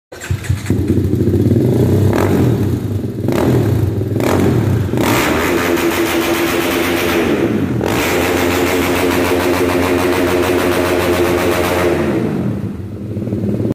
Akrapovic exhaust sound without muffler sound effects free download